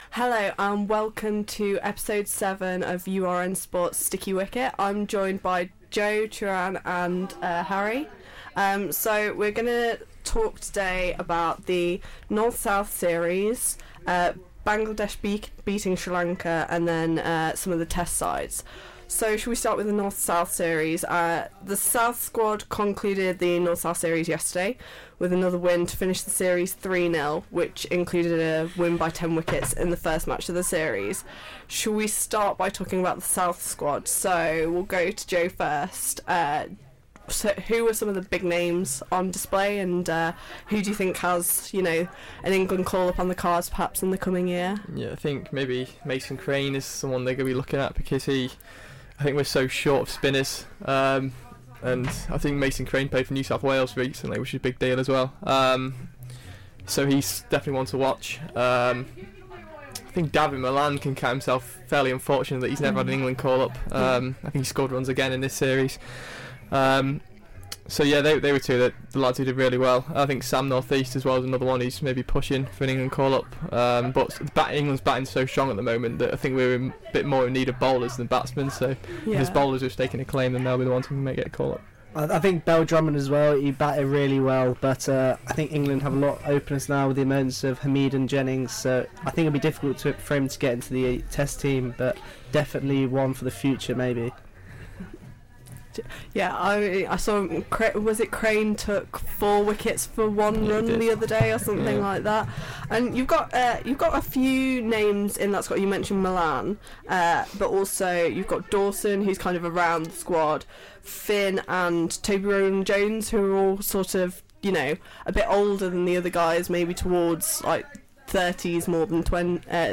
apologies for the background noise during the first 5 minutes